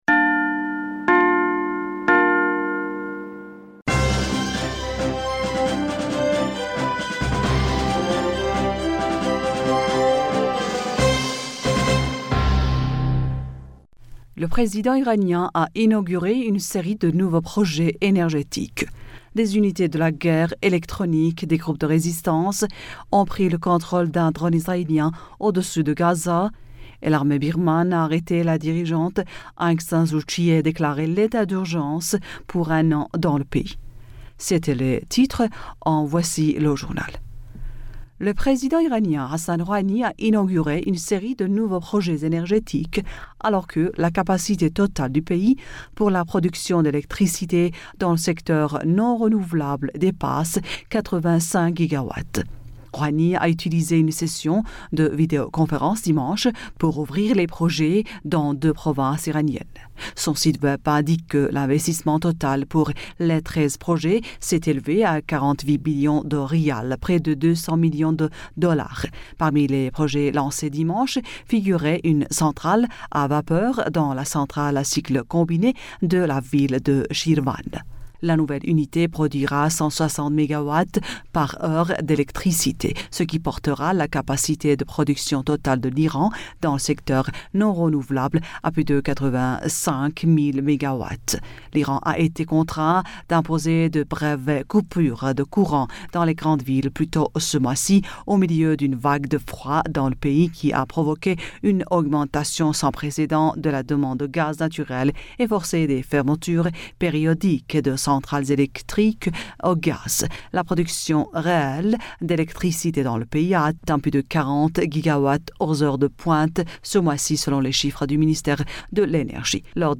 Bulletin d'informationd u 01 Février 2021